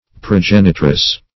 Progenitress \Pro*gen"i*tress\, n.